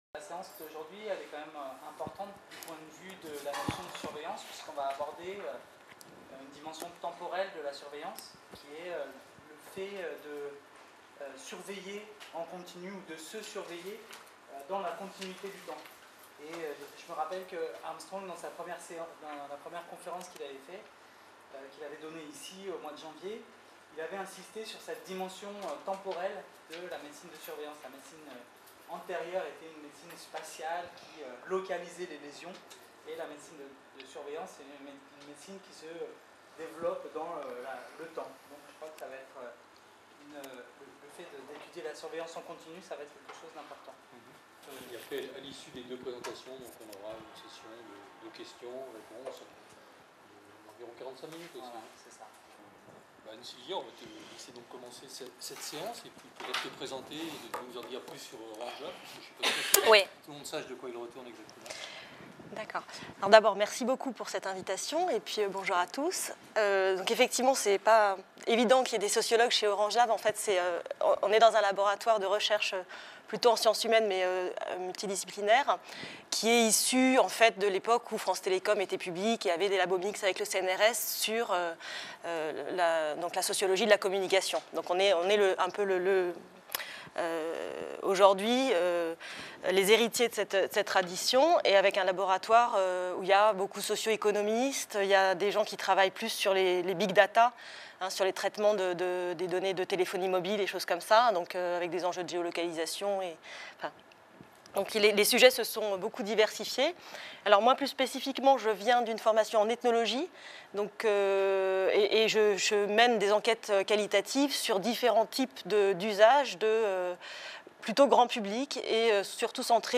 Intervention au séminaire formes de surveillance en médecine et santé publique.